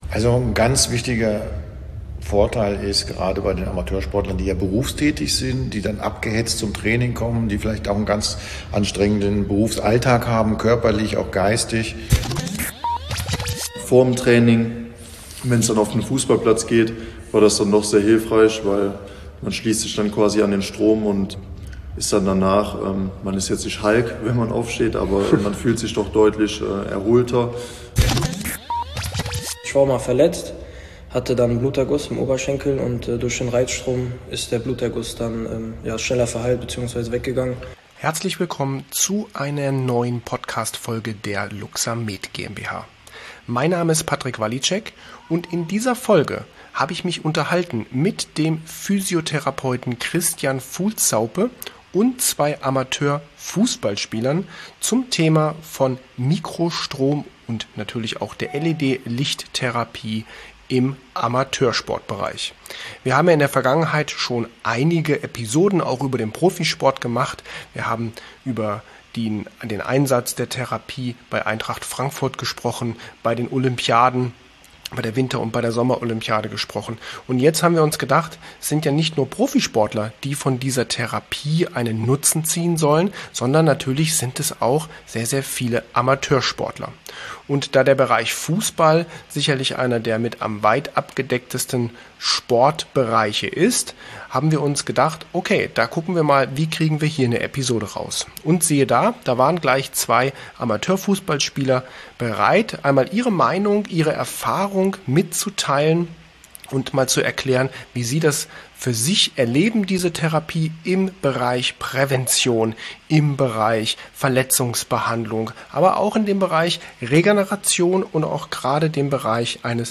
und zwei Amateurspielern aus der Mittelrheinliga (5. Liga). Es geht um die Themen Regeneration, den Spagat zwischen Beruf ./. Sport, Stressbewältigung und die Therapie von Verletzungen.